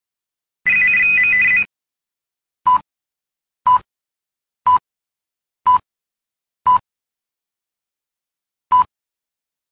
segnale orario.mp3